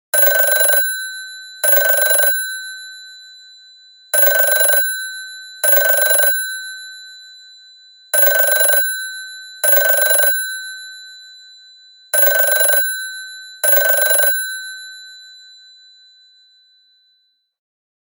Category: Old Phone Ringtones